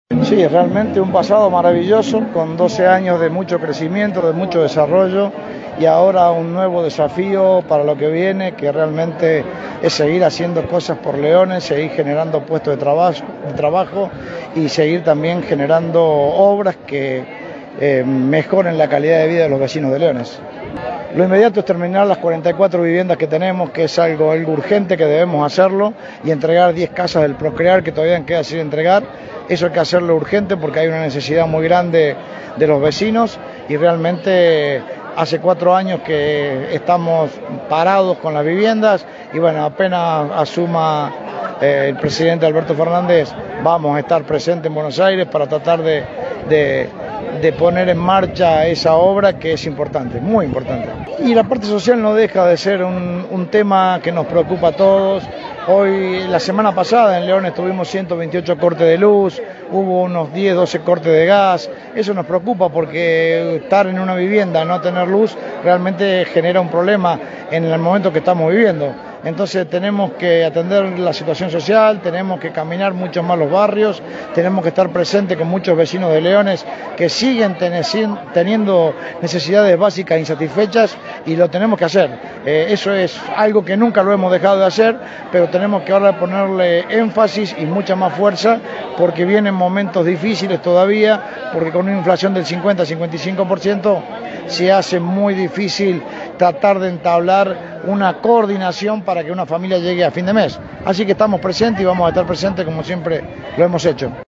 Luego del acto de asunción, el nuevo intendente de Leones, Fabián Francioni, al ser consultado sobre los temas más inmediatos a resolver, citó la necesidad de concluir el barrio de 44 viviendas que se emplaza en cercanías del predio del club Leones y el otorgamiento de las 10 unidades restantes del Procrear, en el sector sur de la ciudad.